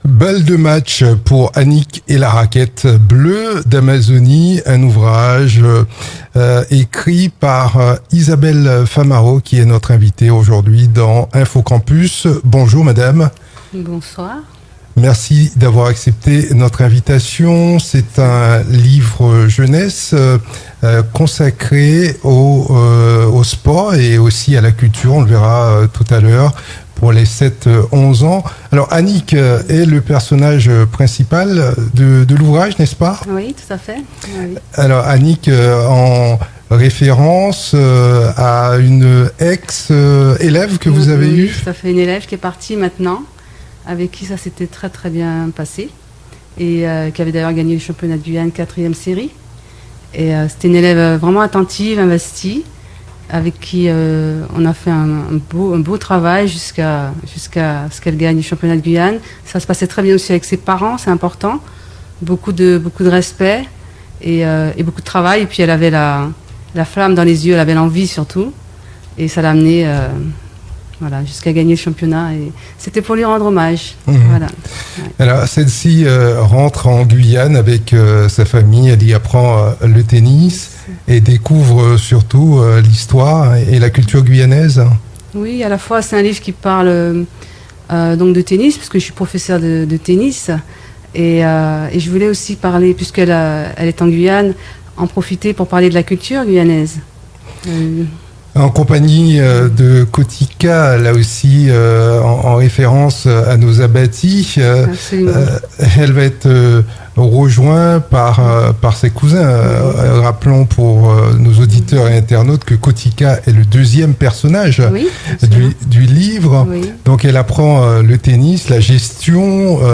Podcast Radio mayouri